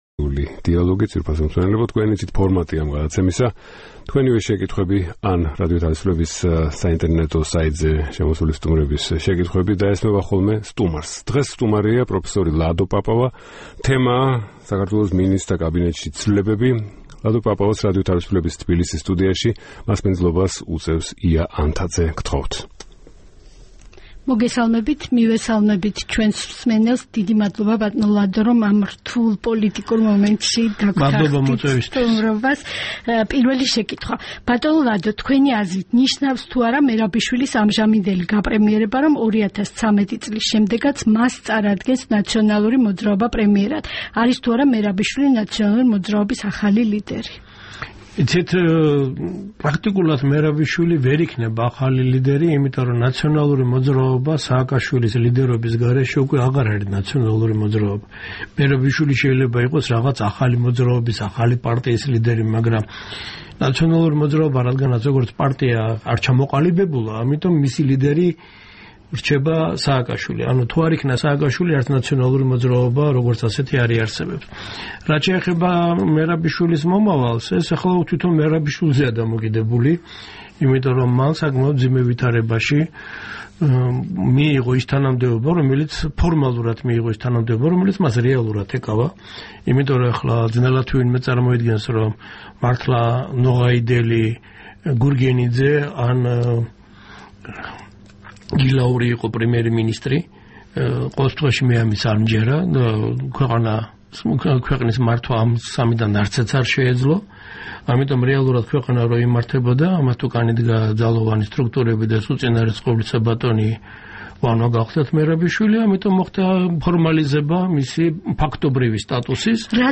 „ინტერაქტიული დიალოგის“ სტუმარი, ლადო პაპავა საუბრობს სამთავრობო ცვლილებების შესახებ.